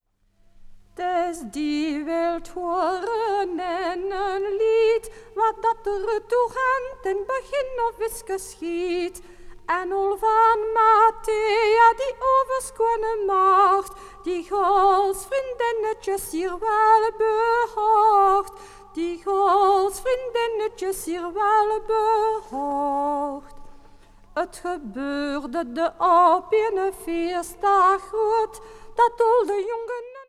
begijnen van het Groot Begijnhof van Sint-Amandsberg
Klavier en solozang:
Studio G.S.T. Gent.